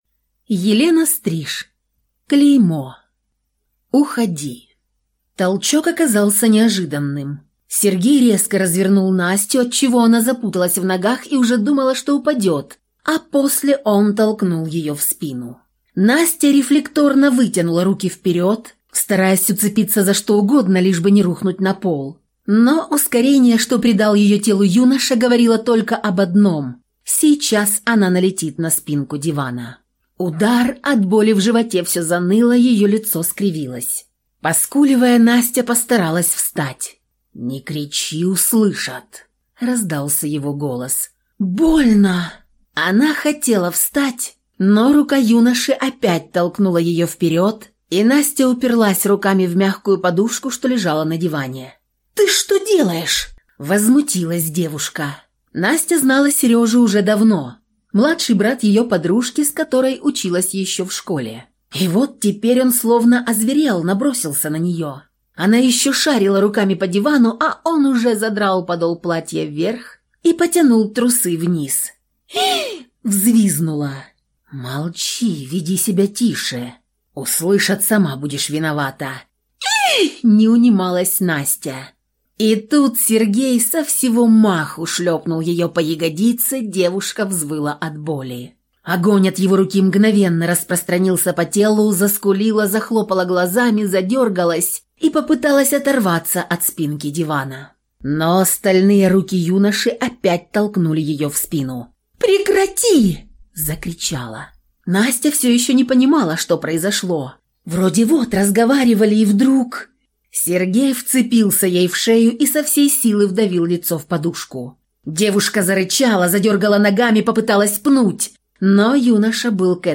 Aудиокнига Клеймо